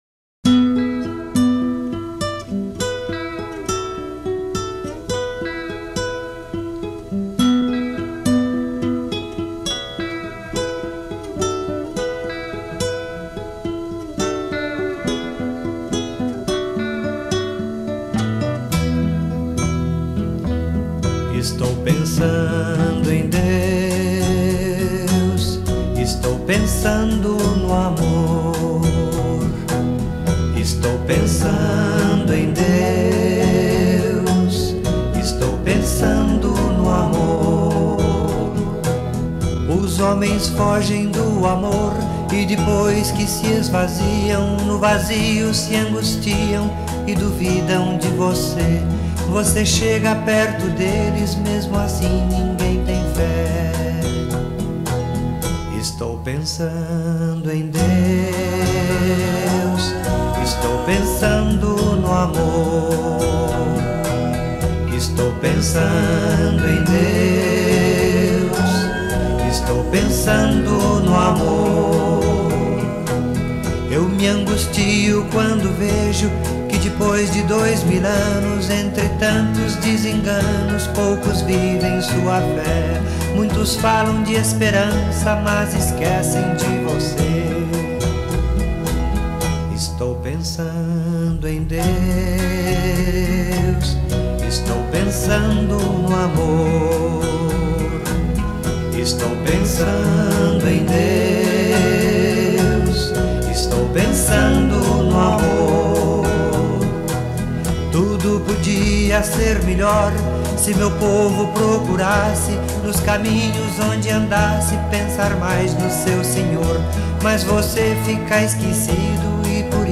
HINO